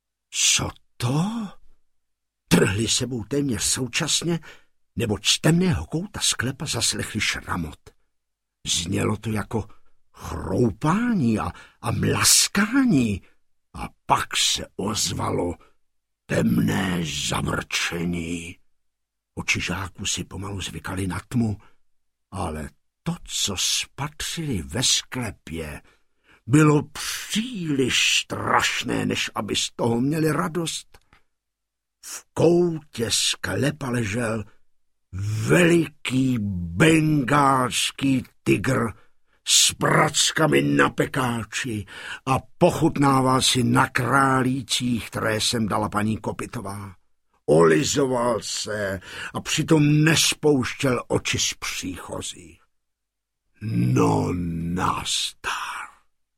Kopyto a mnouk audiokniha
Ukázka z knihy